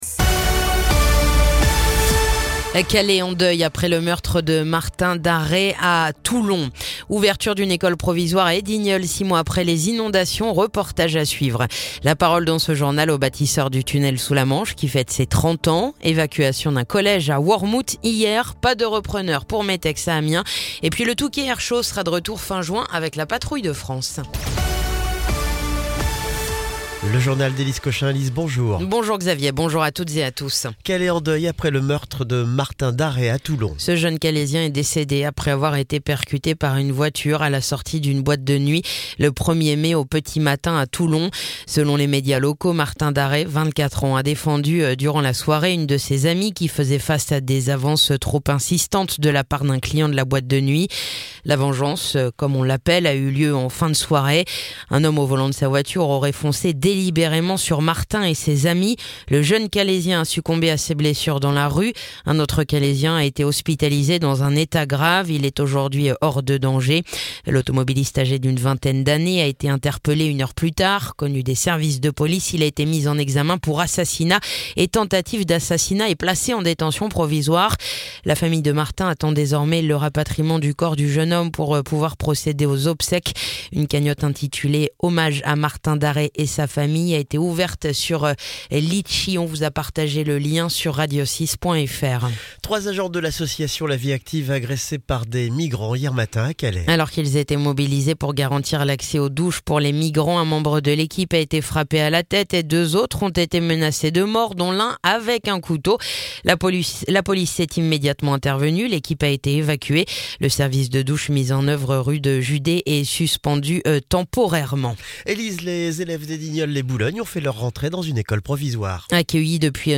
Le journal du mardi 7 mai